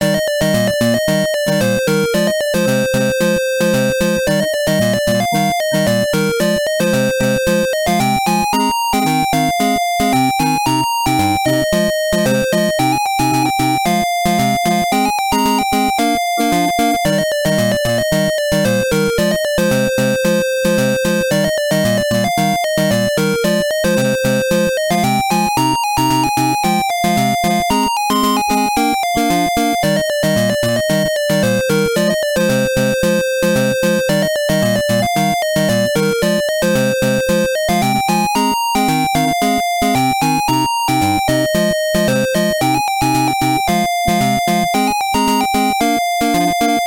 Contestant Selection Theme